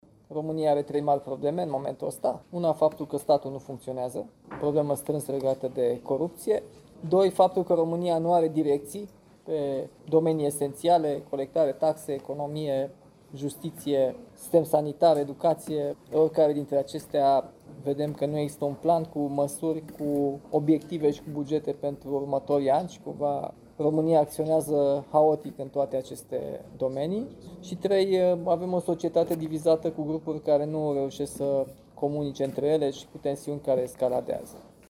Prezent astăzi, la Constanța, la o întâlnire cu susținătorii, primarul general al Capitalei a vorbit despre problemele cu care se confruntă România.